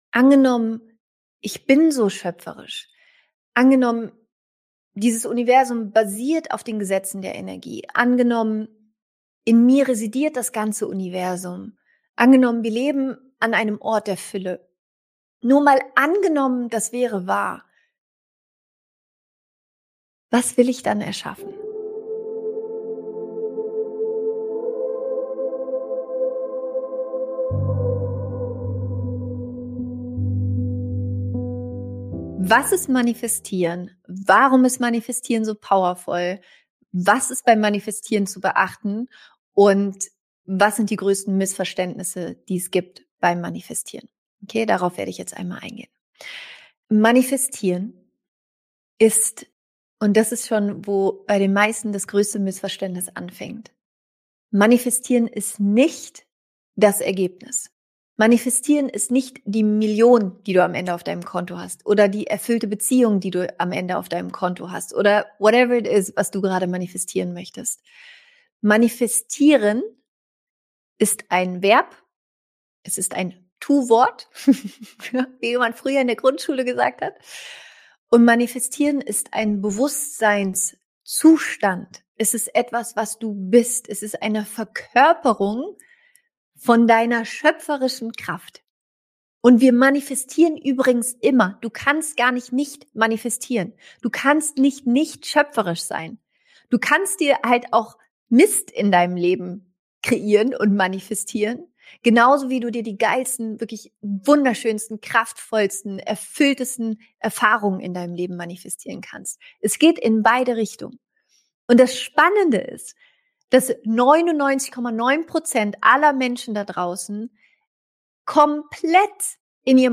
Diese Folge ist ein bisher unveröffentlichter Ausschnitt aus meiner Manifestation Night 2024.